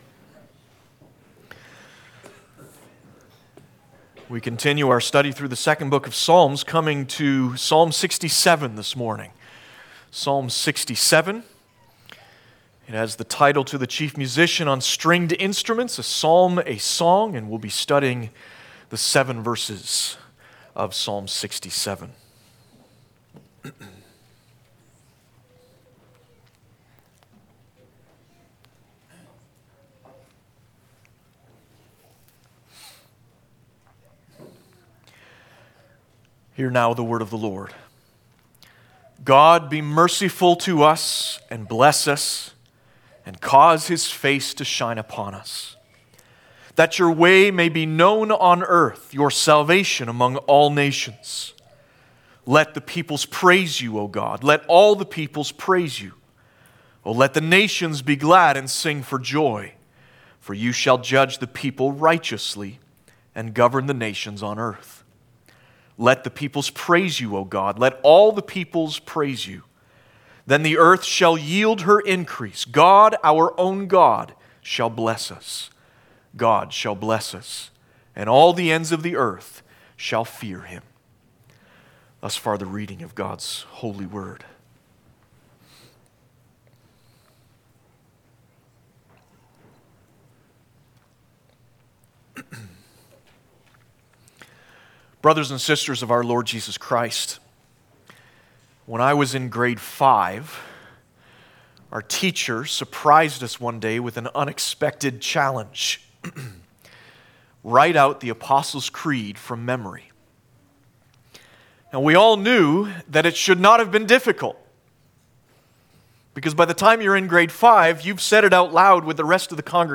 5.-sermon-Psalm-67.mp3